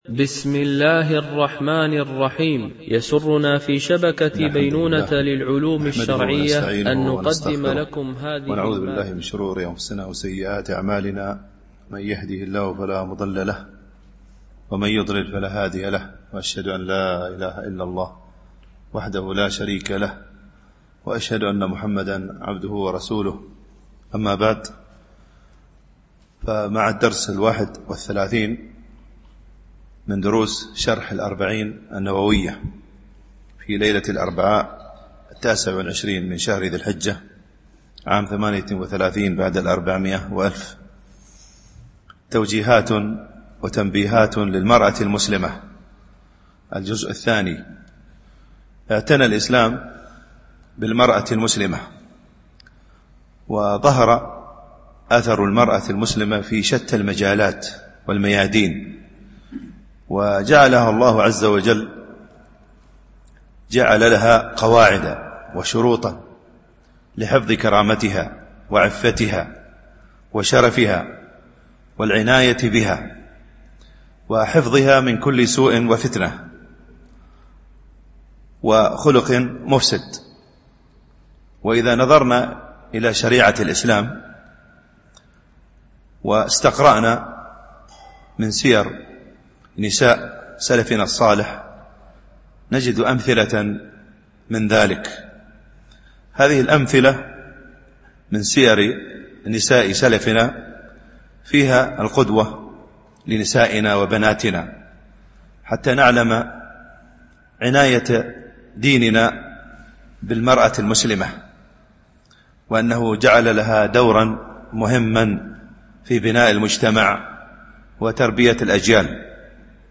شرح الأربعين النووية ـ الدرس 31 (الحديث 18)